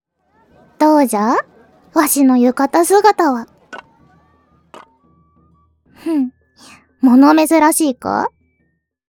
ハコネクト所属メンバーが収録した「夏のおでかけ」をテーマにしたコンセプトボイスを是非お楽しみください！
ボイスサンプル